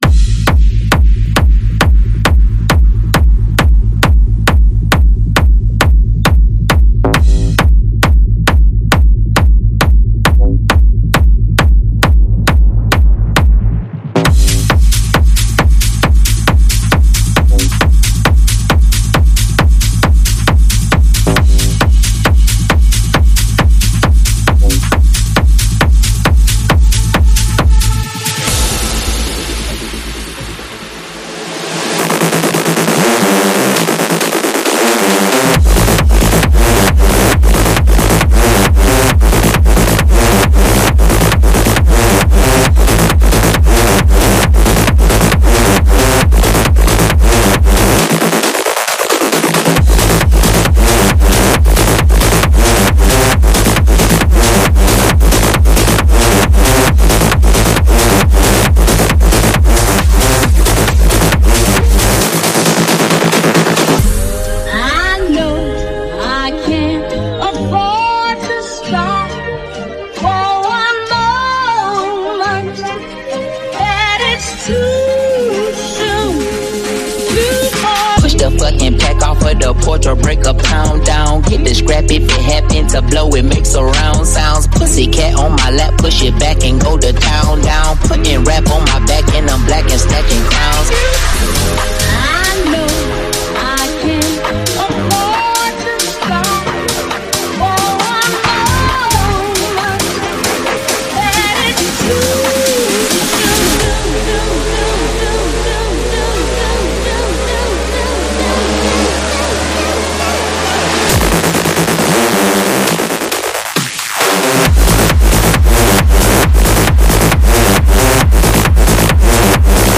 试听文件为低音质，下载后为无水印高音质文件